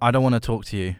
Update Voice Overs for Amplification & Normalisation
i dont want to talk to you.wav